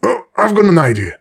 brickmove03.ogg